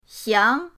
xiang2.mp3